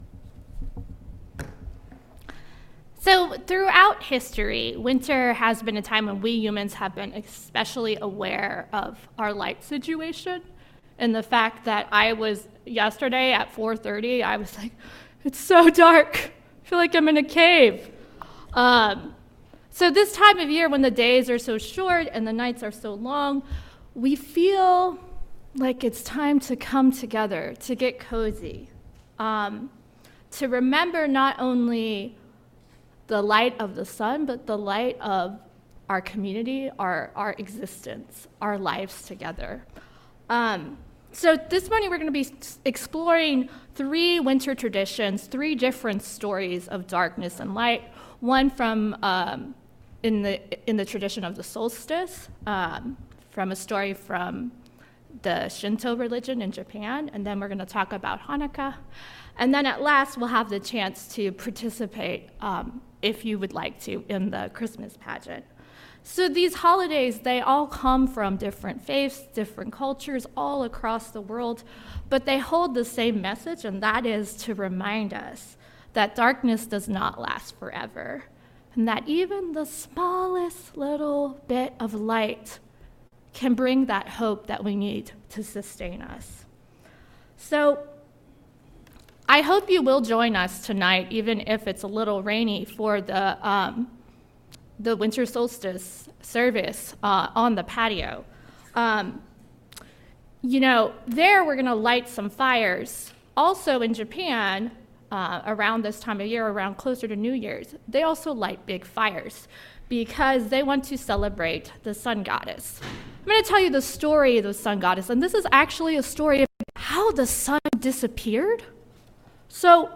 Throughout ancient cultures, light returning from darkness in nature led to symbolic tales of the same “miracle” occurring in human lives and communities. Today’s intergenerational service tells three of these as we mark the winter solstice, share the story of Hanukkah, and together bring to life … read more.